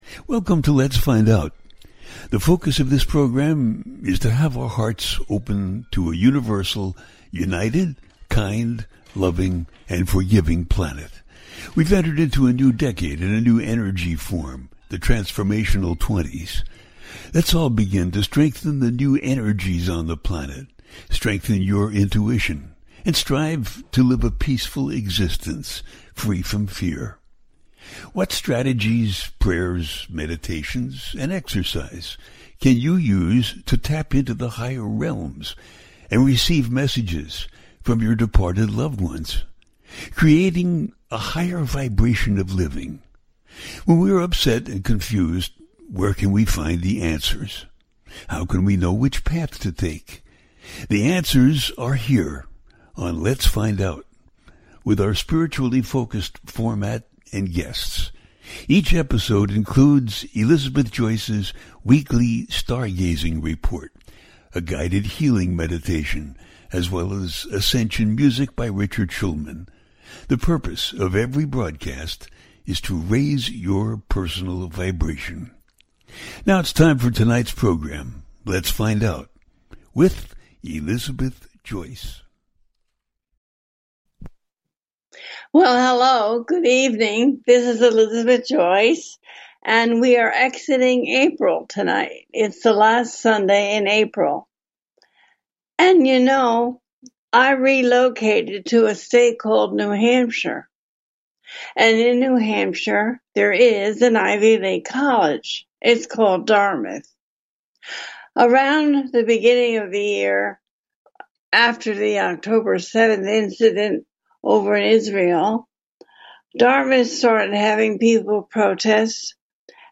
Ending the Hate, What’s Coming in May - A Teaching Show